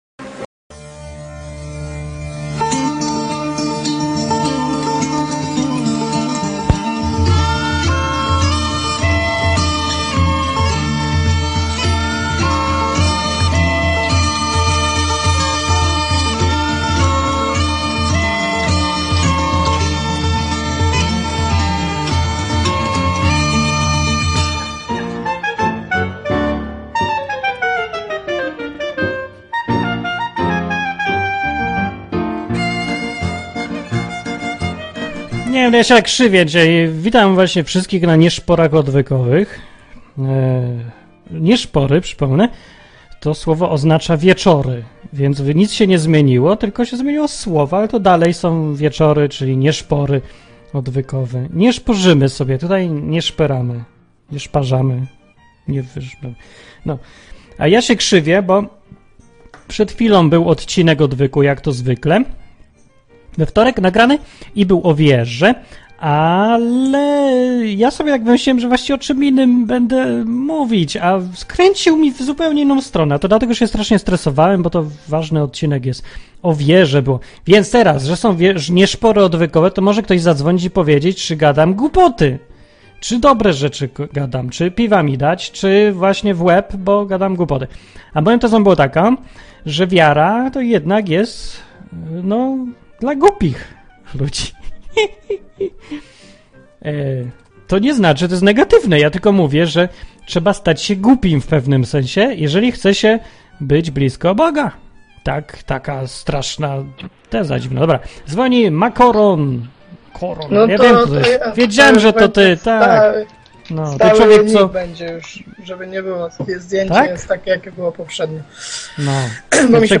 Nieszpory odwykowe na żywo są pełne niezwykłych opowieści.
Rozmowy ze słuchaczami na tematy Biblii, Boga, księdza, egzorcyzmów.